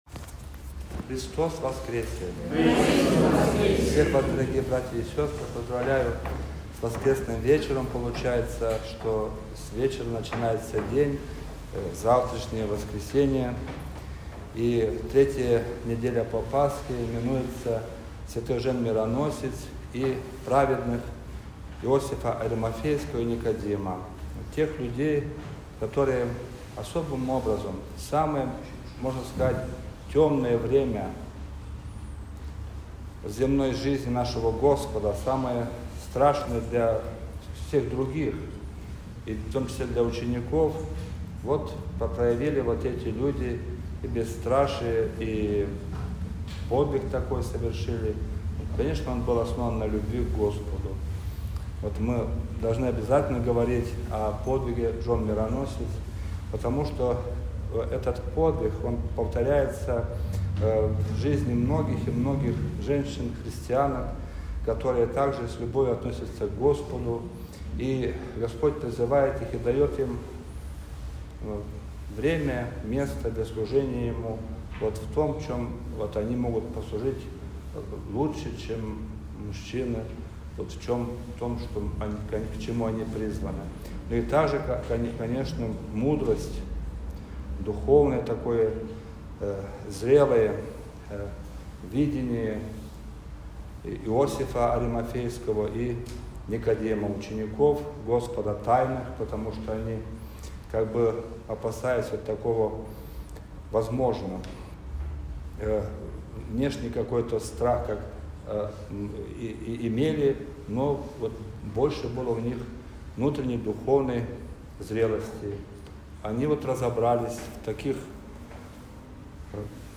Проповедь
после Всенощного бдения